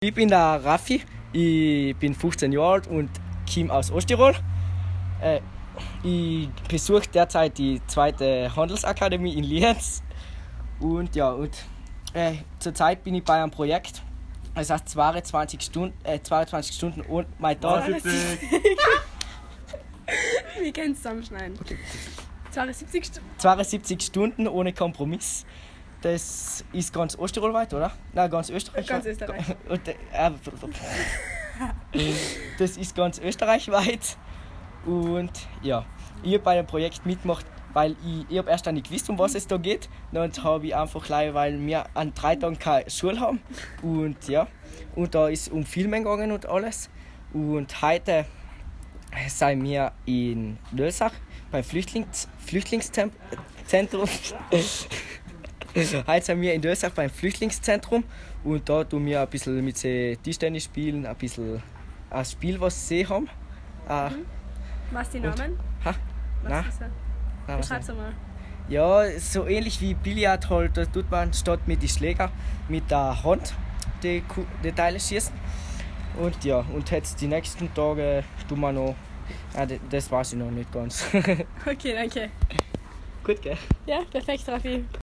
Dort wird im Flüchtlingsheim für das Projekt „Mei Tog“ gedreht.
Egal ob Tischtennis, Tischfußball oder Karaokesingen, alles wird von der Projektgruppe auf Video festgehalten um zu zeigen, dass verschiedene Herkunftsländer überhaupt nichts am Tagesablauf ändern und wir unterm Strich doch sowieso alle gleich sind.
fluechtlingsheim-2.mp3